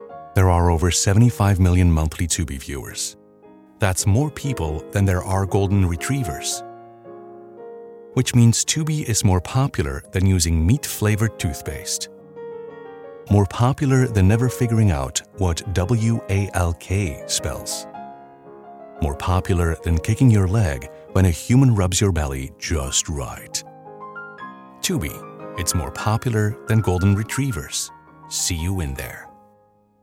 Male
Approachable, Assured, Authoritative, Bright, Character, Confident, Conversational, Cool, Corporate, Deep, Energetic, Engaging, Friendly, Funny, Gravitas, Natural, Posh, Reassuring, Sarcastic, Smooth, Soft, Upbeat, Versatile, Wacky, Warm, Witty
Microphone: Sennheiser MKH416 and Neumann TLM103
Audio equipment: Studiobricks One Custom, UA Apollo Twin